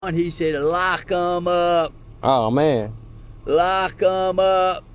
lock em up Meme Sound Effect